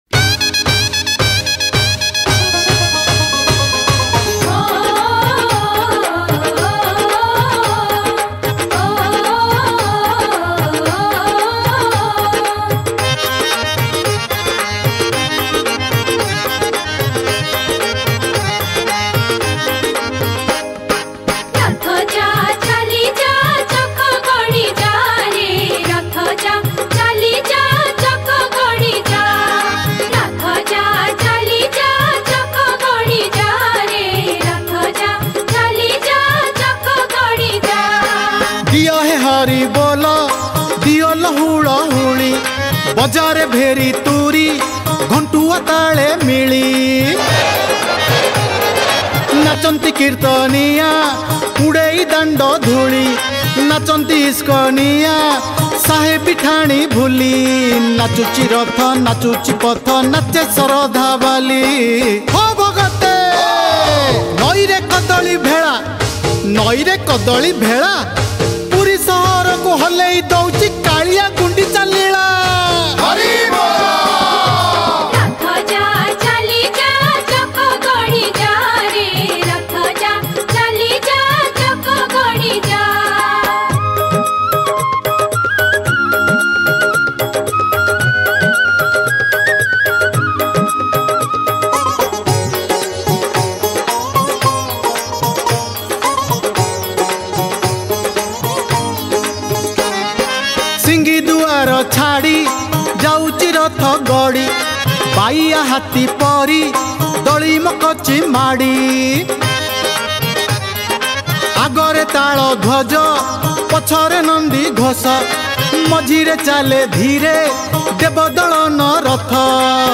Ratha Yatra Odia Bhajan 2000-21 Songs Download